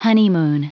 Prononciation du mot honeymoon en anglais (fichier audio)
Prononciation du mot : honeymoon